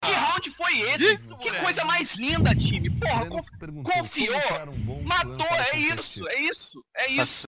8-second meme soundboard clip — free, in-browser, no signup, no download required.
A Portuguese phrase asking "what round was that?" — a gaming reaction sound perfect for competitive game streams or highlight moments.